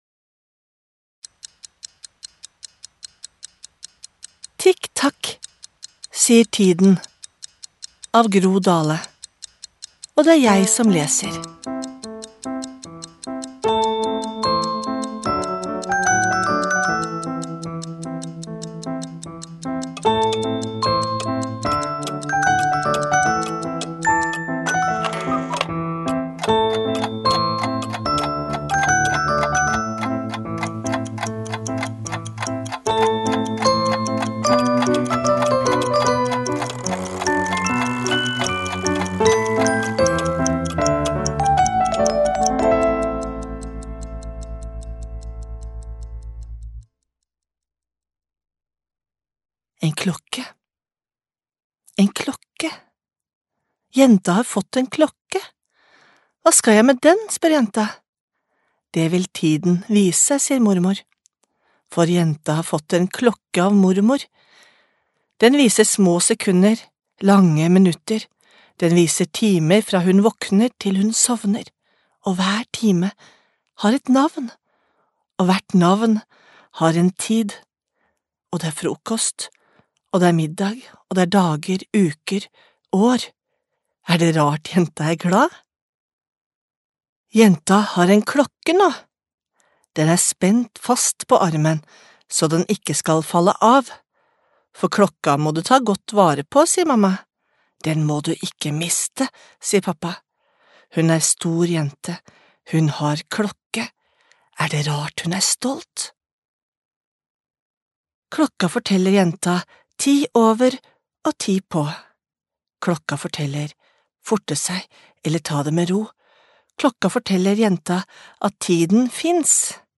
Tikk takk, sier Tiden (lydbok) av Gro Dahle